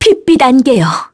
Lewsia_B-Vox_Skill4-1_kr.wav